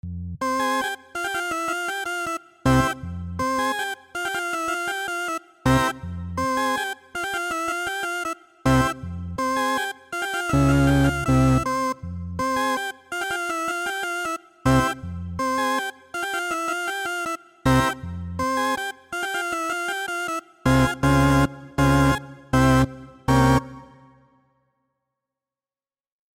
3、Red Animal（电子风琴）
The Doors、the Animals、the Monkees 等乐队使 Vox Continental 成为 60 年代最著名的电子风琴之一。
这种情况下的基本波形是低通滤波方波。
该风琴仅提供两种不同的音域：Sine 和 Master，具有 4 种不同的脚长。